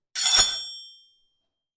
SWORD_18.wav